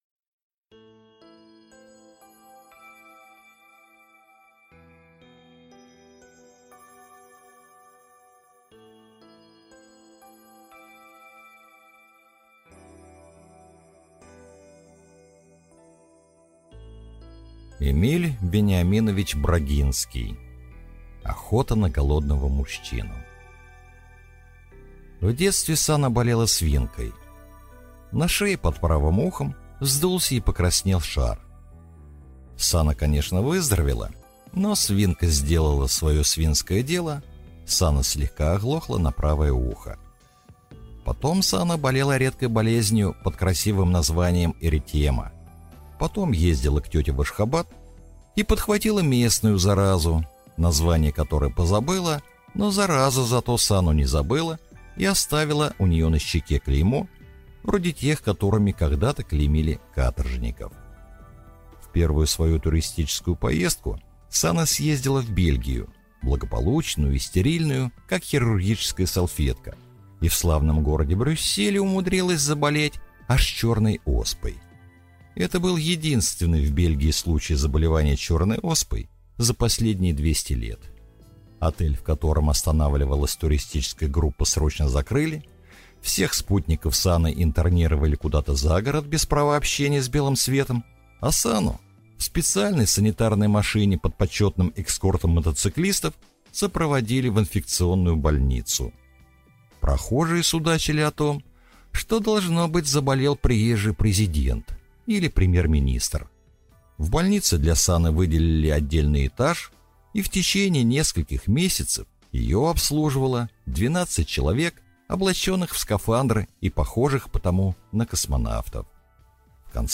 Аудиокнига Охота на голодного мужчину | Библиотека аудиокниг